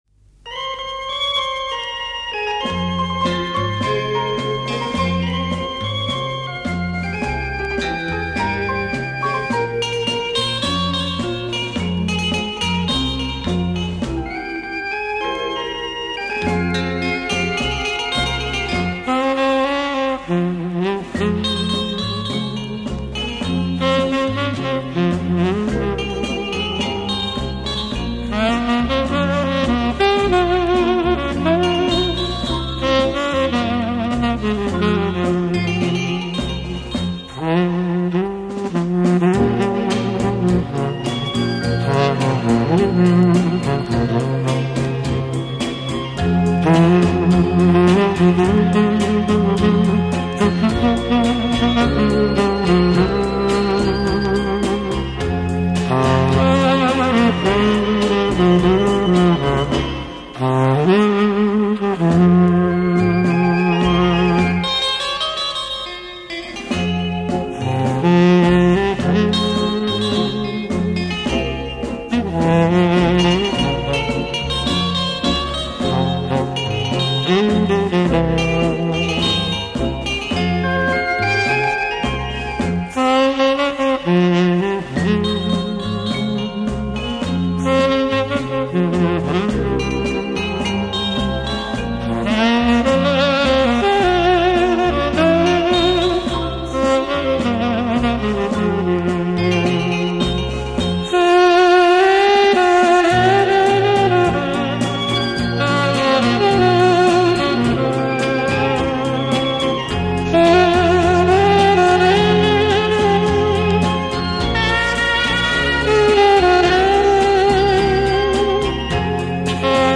Саксофон 3